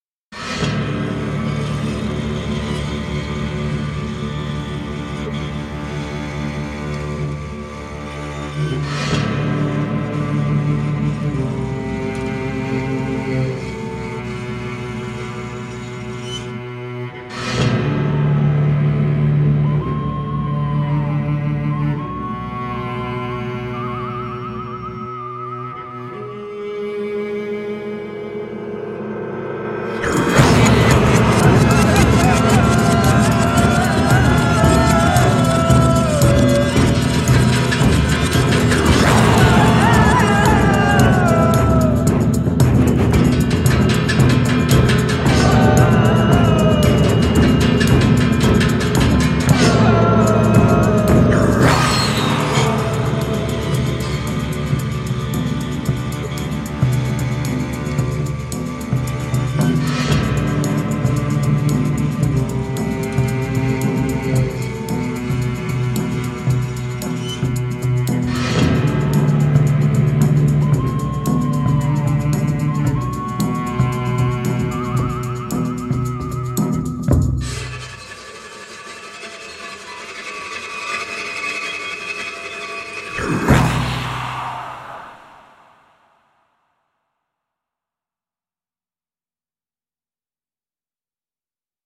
这种无声乐器用破裂且划伤的仿古木发出的嘎嘎声和轰鸣声具有鲜明的特征。这种竖琴被鞠躬，敲敲，刺伤，重击，弹拨，弹拨和惩罚，以产生您从未听过的可怕的一种声音。
‣弓：会导致您发疯的划痕，重击和颤音
‣敲门：敲击竖琴的木质主体
•闪光旋钮可在混音中添加鬼混效果（具有额外的八度和声）
•反向按键开关（键盘上的D0）可将此竖琴转换为令人讨厌的音景/通过按键盘上的C0返回正常播放